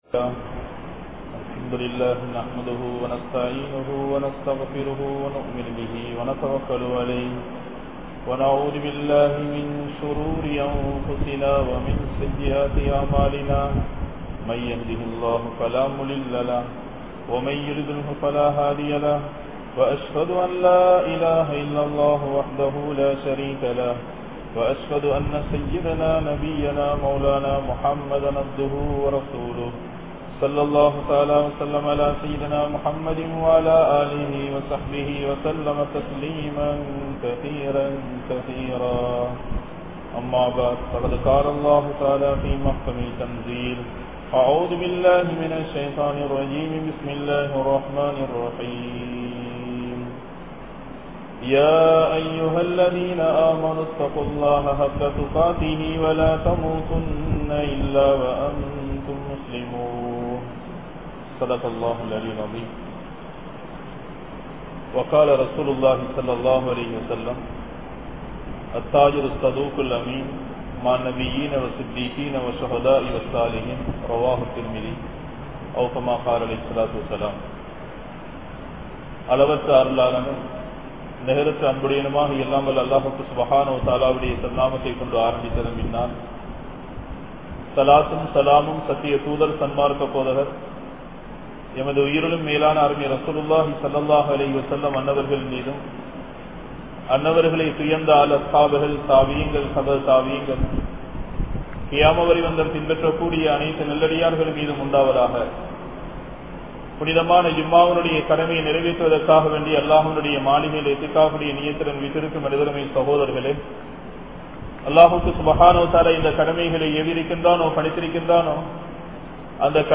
Islamiya Viyafaaram | Audio Bayans | All Ceylon Muslim Youth Community | Addalaichenai